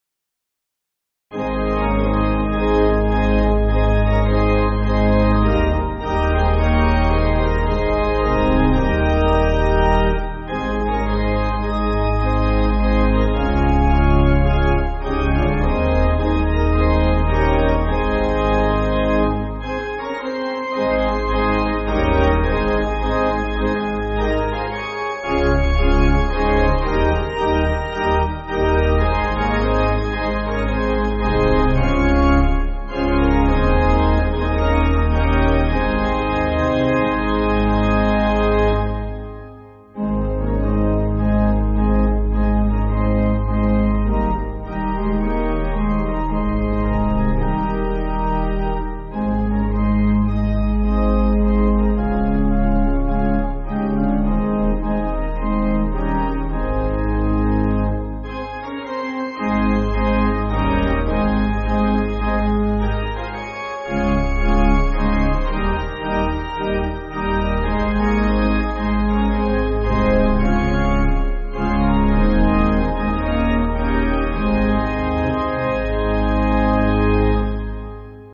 (CM)   5/Ab